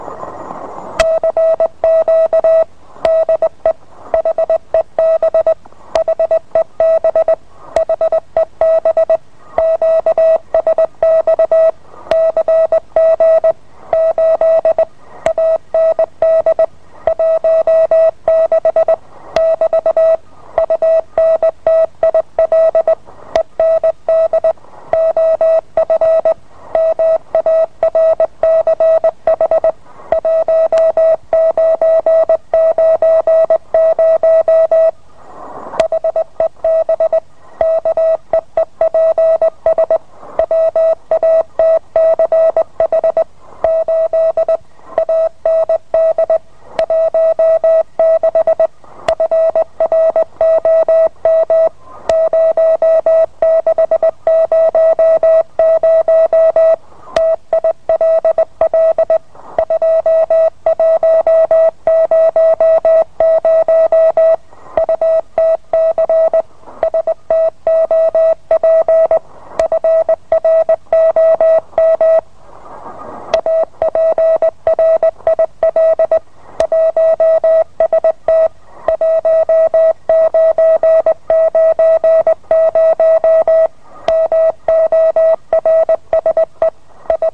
Letztes Morse-CQ von HEB im Jahr 1990
CW_CQ_de_HEB_1990.mp3